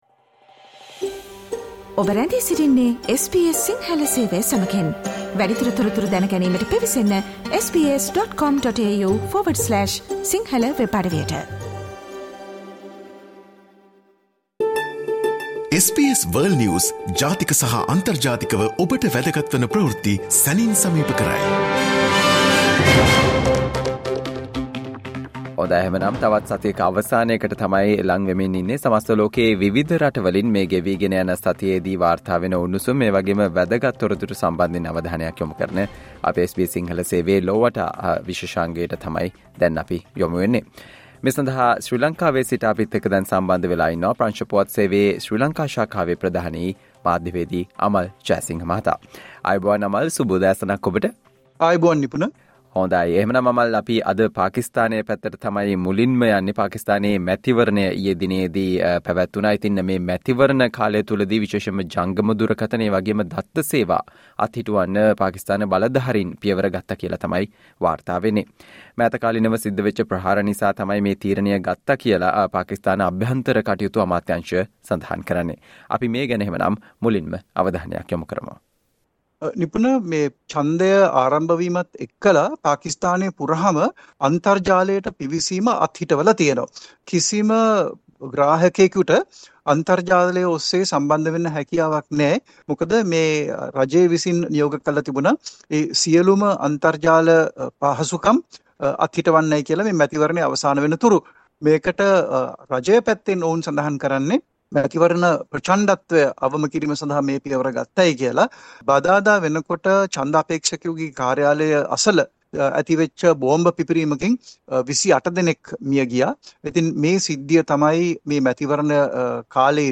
listen to the world’s most prominent news highlights.